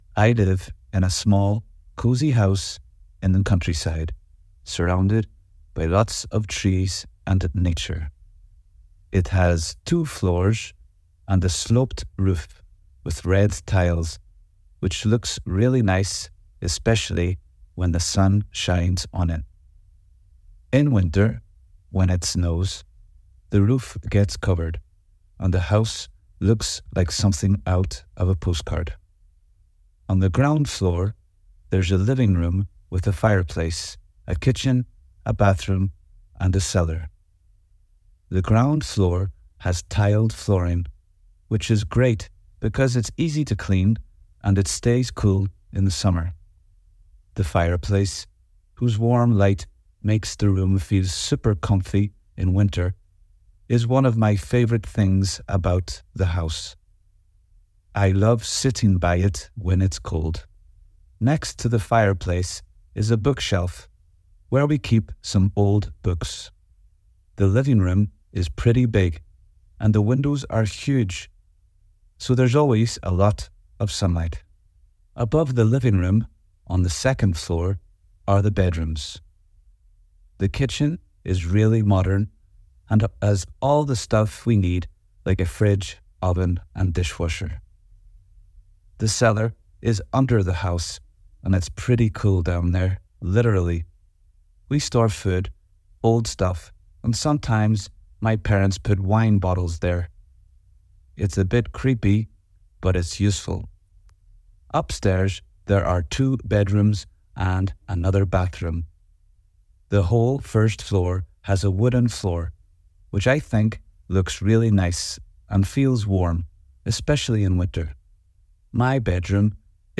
my_house_scottish.mp3